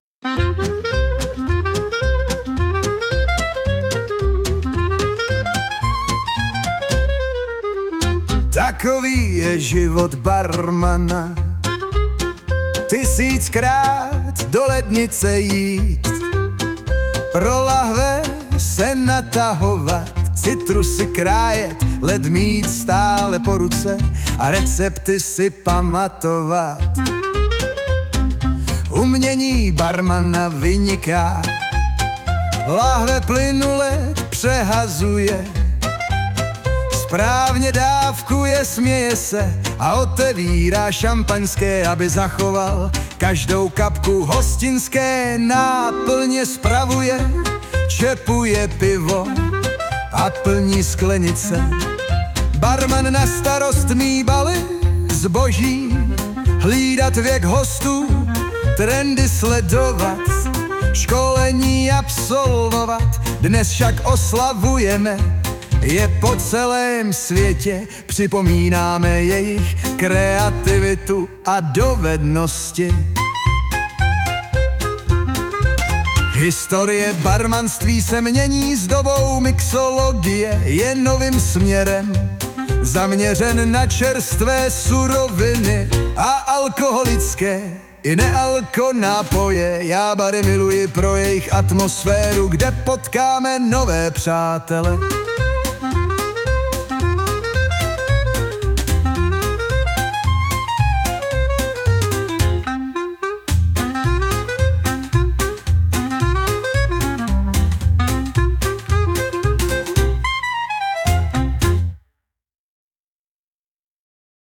Dynamic klezmer song – from a Czech news article